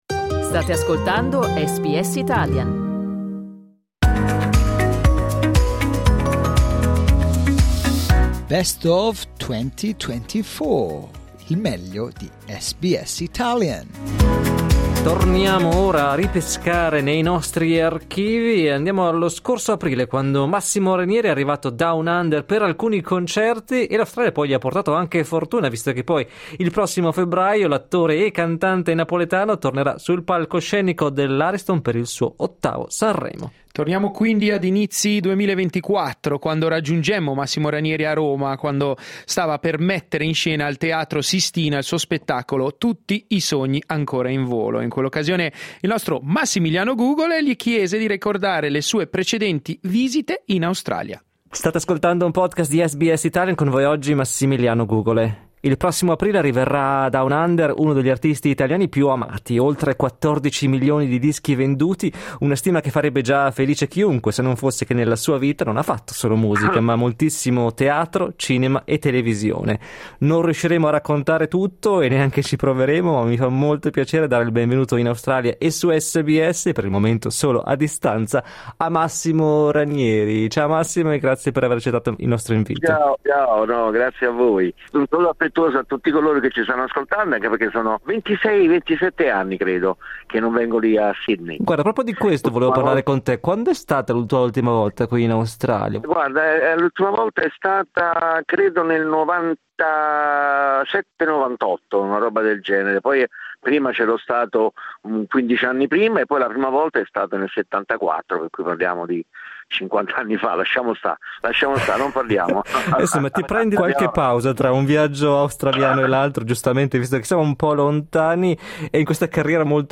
Dai nostri archivi un'intervista con l'artista napoletano che ripercorre con noi la sua carriera.